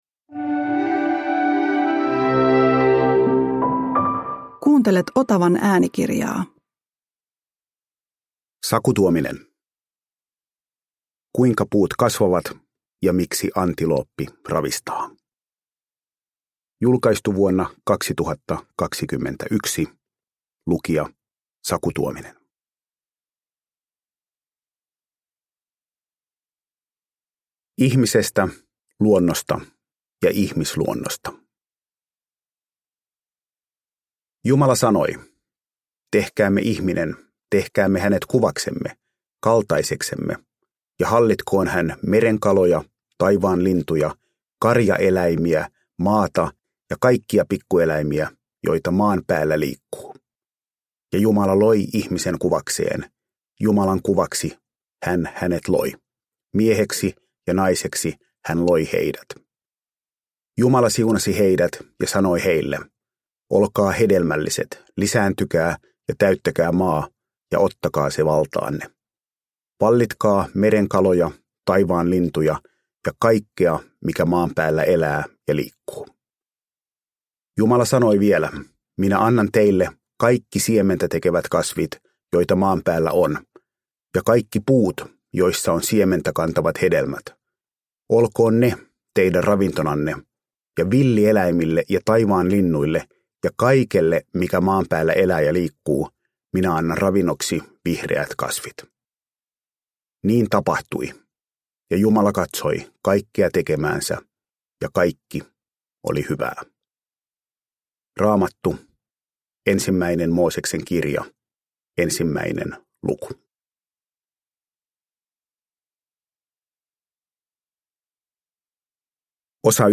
Kuinka puut kasvavat – Ljudbok – Laddas ner